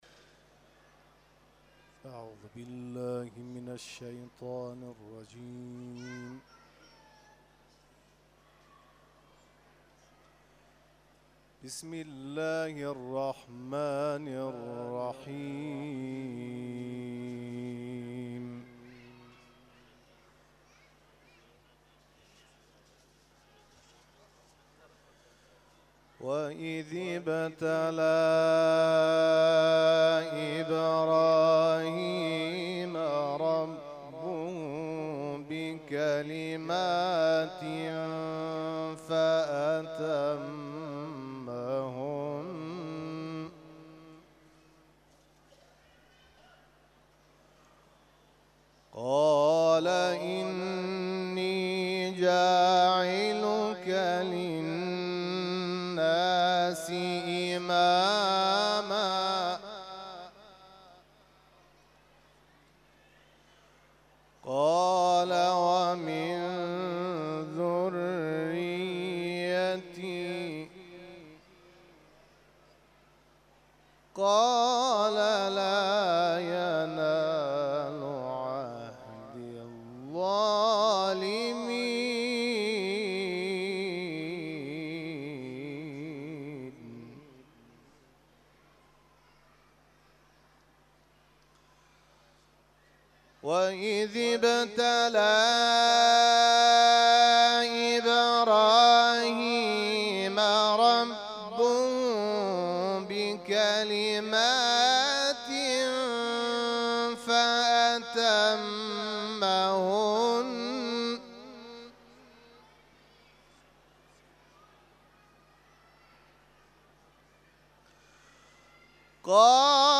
مراسم ولادت امام رضا(ع)
محافل و مراسم قرآنی
تلاوت قرآن کریم